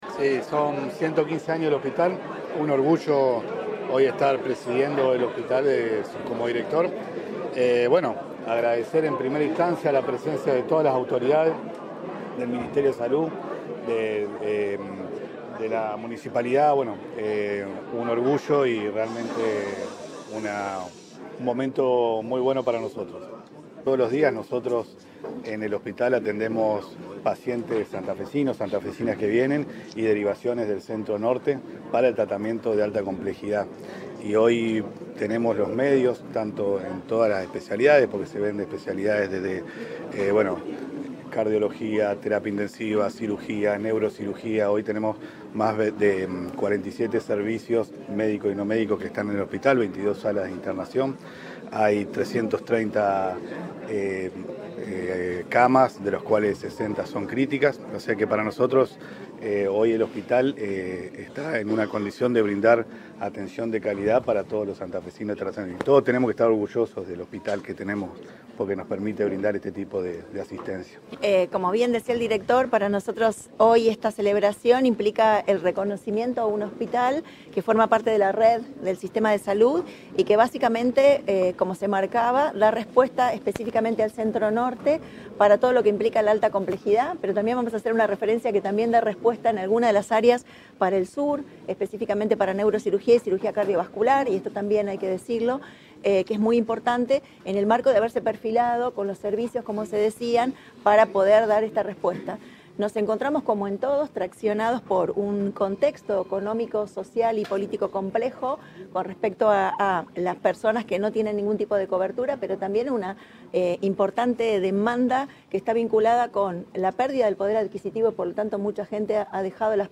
En un acto realizado en el Auditorio del hospital, la secretaria de Salud, Andrea Uboldi, expresó que “esta celebración implica el reconocimiento a un hospital que forma parte de la Red del Sistema de Salud para todo lo que implica la alta complejidad del centro norte provincial, pero también da respuesta en algunas de las áreas del sur, específicamente para Neurocirugía y Cirugía Cardiovascular”.